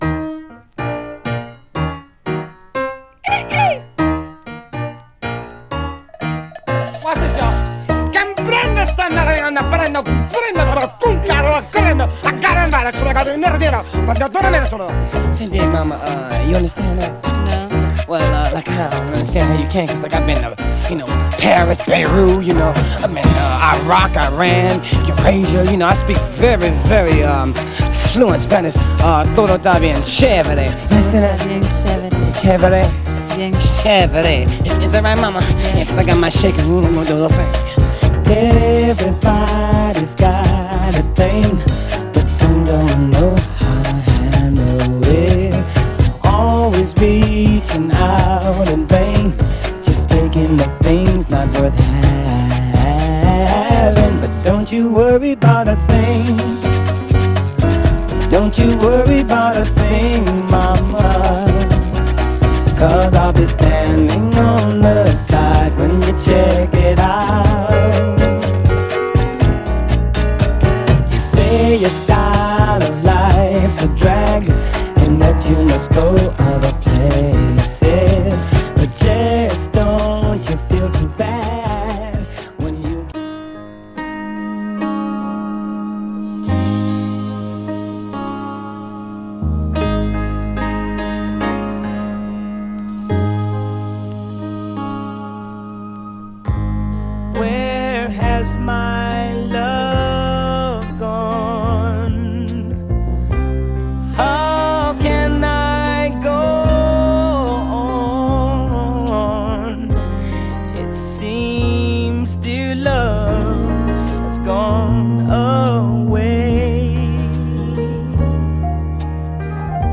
盤はエッジ中心にごく細かいスレ箇所ありますが、グロスがありプレイ概ね良好です。
※試聴音源は実際にお送りする商品から録音したものです※